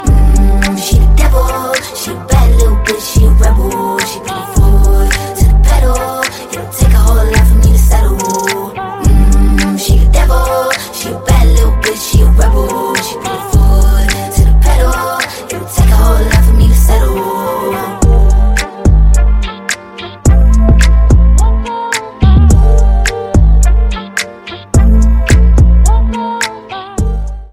Korean girl group